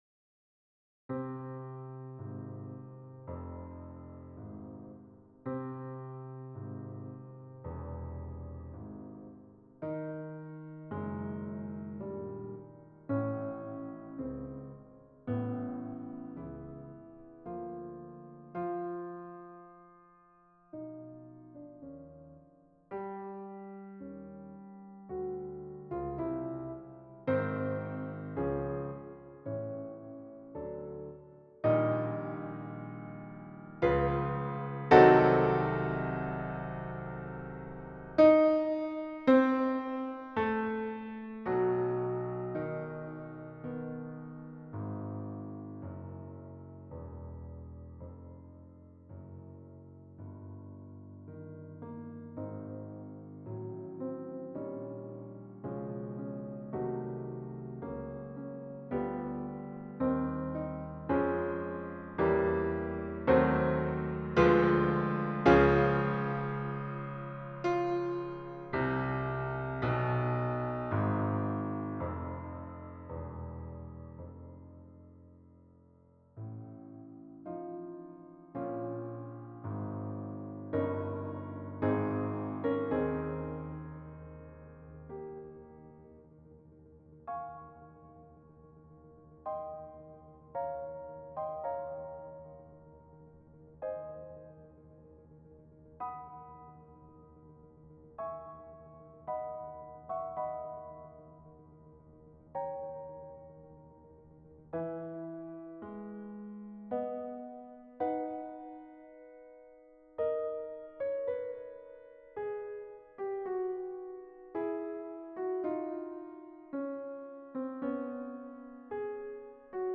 Symphony No. 1 - Early Adagio: Arranged for FOUR Pianos